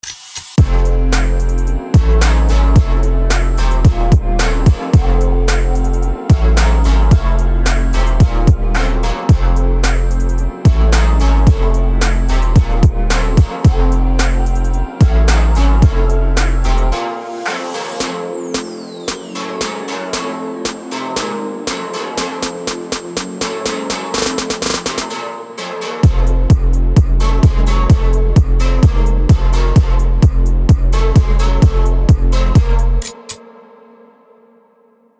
Elektronickou hudbu tvořím přes 4 roky.